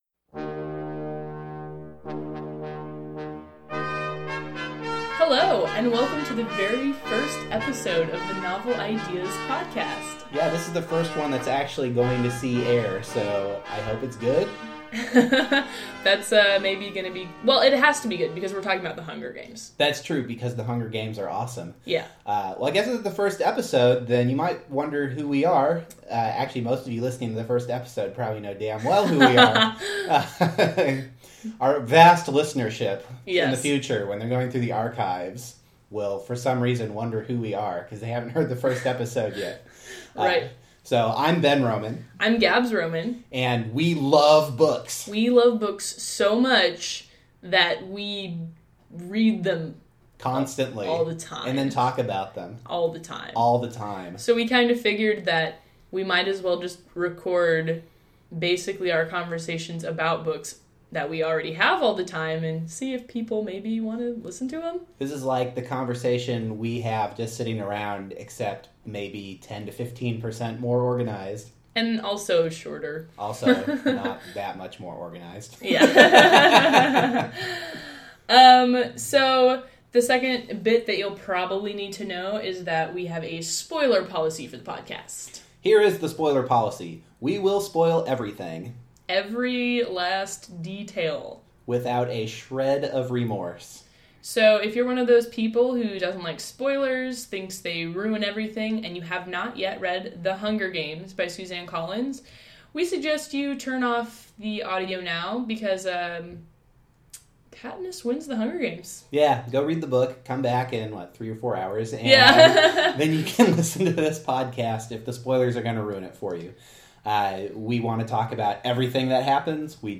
In this episode, we cover Katniss and why she is a badass, Peeta’s lack of hunting skills, reality TV, feminism, and Thresh’s weird lack of verbal skills. Try not to be terrified by our bizarre and inaccurate renditions of regional accents as we break down this dystopian YA thriller.